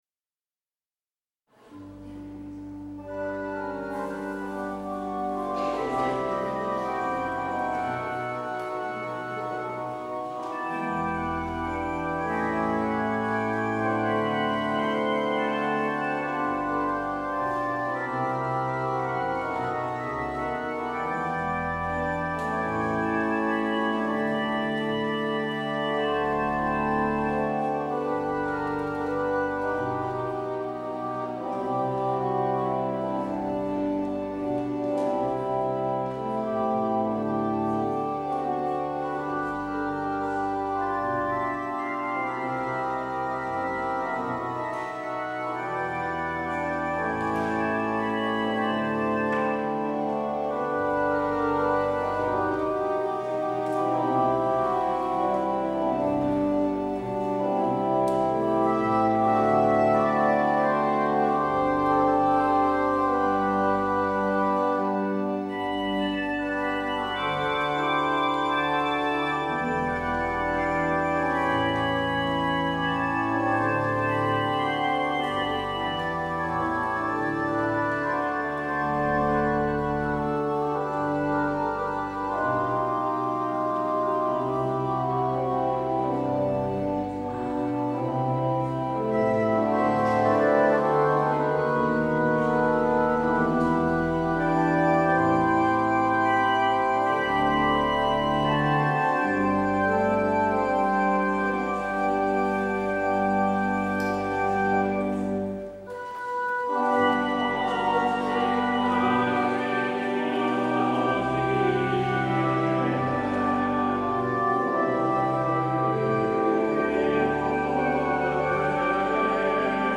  Luister deze kerkdienst terug